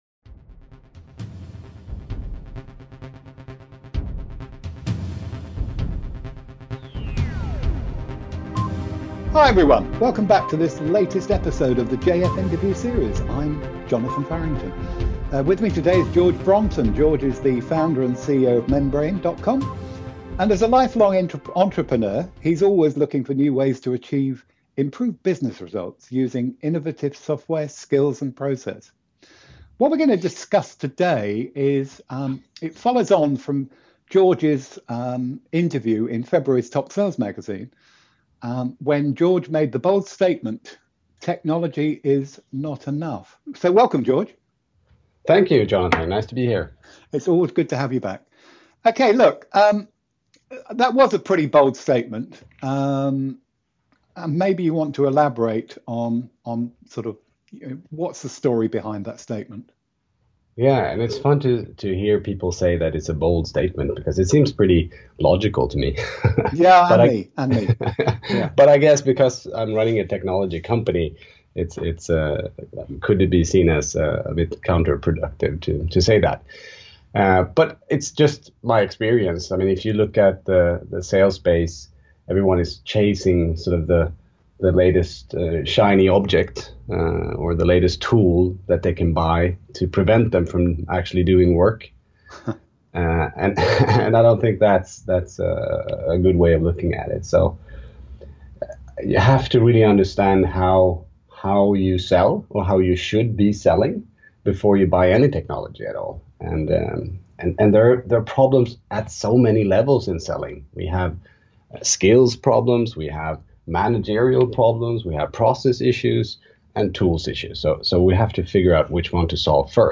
Category: Interview, Sales Tools, Sales Trends